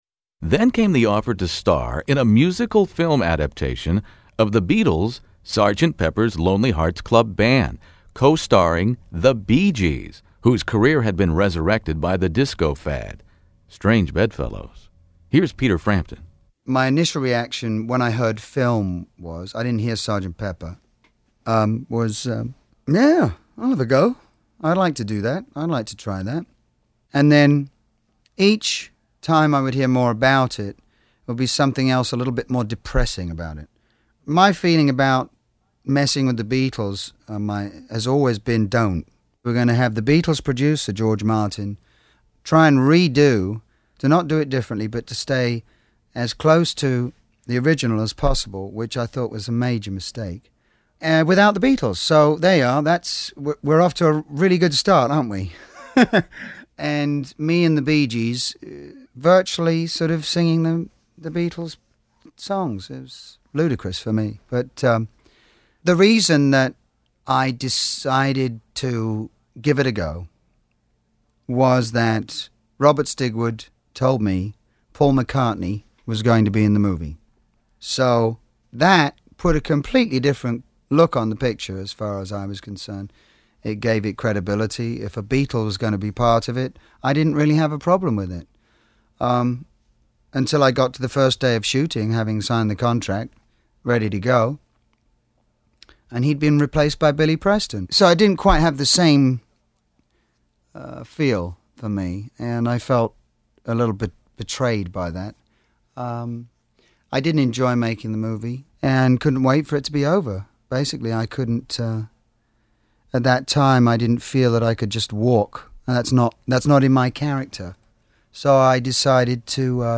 On paper the  concept couldn’t possibly miss, but as Peter Frampton reveals in this classic rock interview  In the Studio, it was all lipstick on a pig.